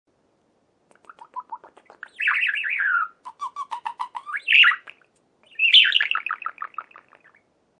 دانلود صدای مرغ عشق جفت از ساعد نیوز با لینک مستقیم و کیفیت بالا
جلوه های صوتی